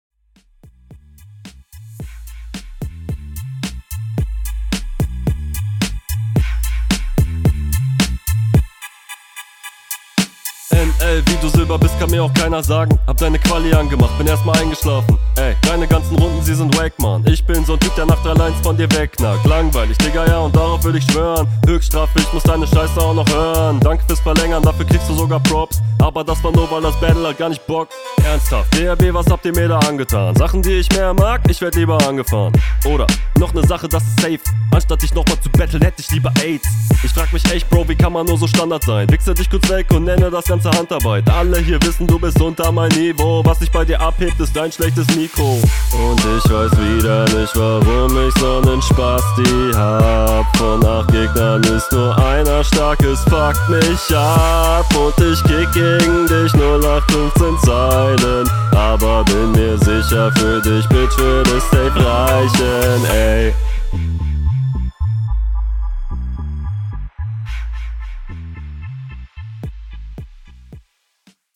Hook müsste man komplett anders mixen und doubles drunter legen, dann wäre das sick af.